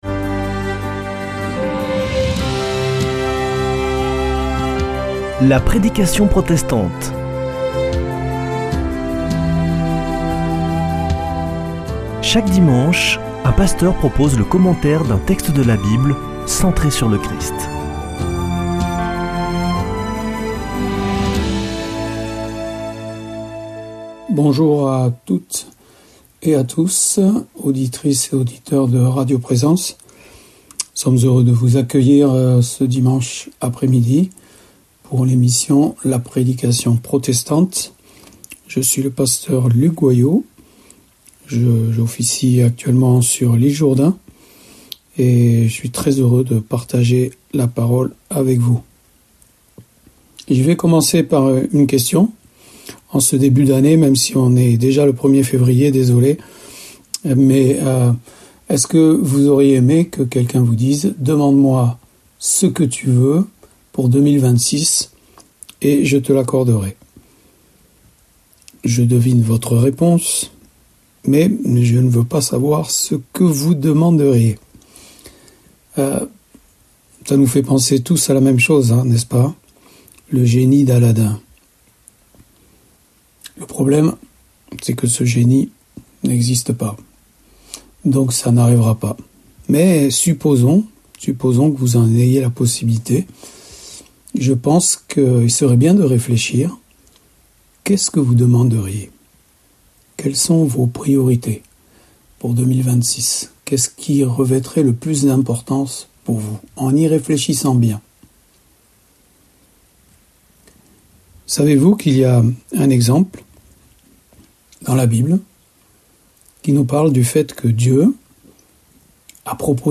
La prédication protestante